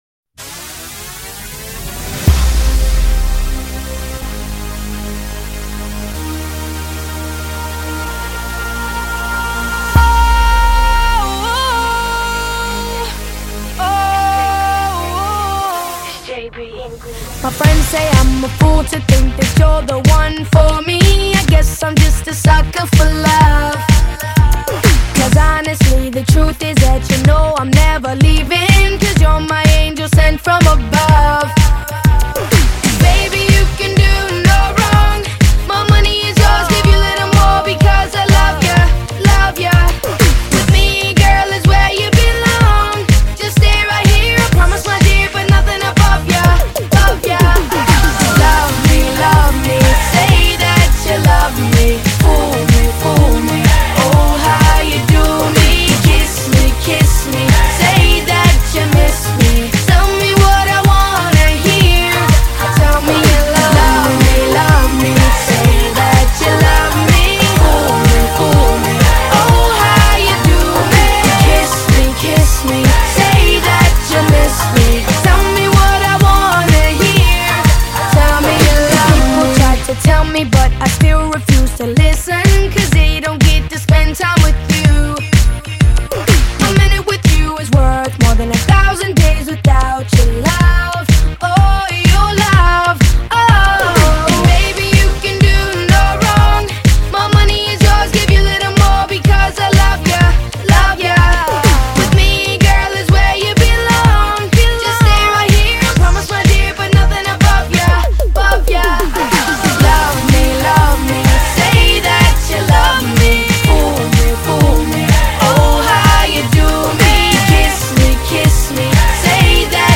kategori : lagu barat
genre : pop , rock